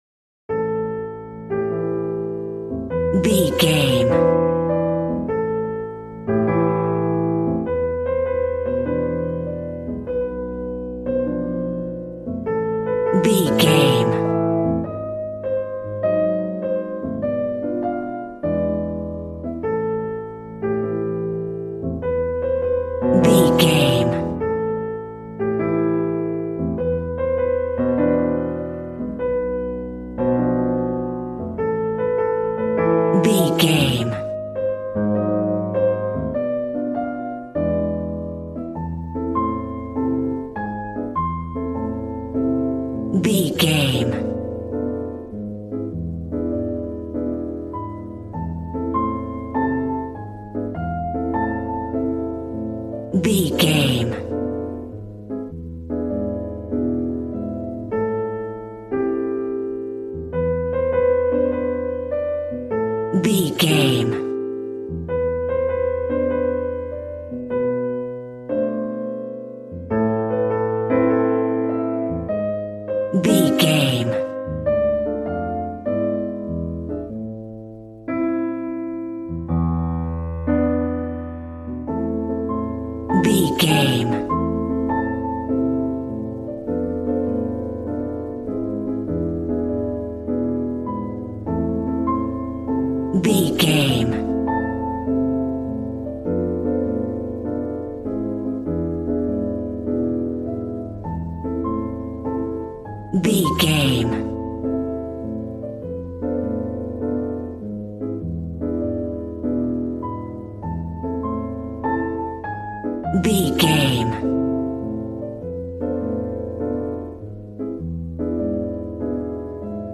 Smooth jazz piano mixed with jazz bass and cool jazz drums.,
Aeolian/Minor
smooth
drums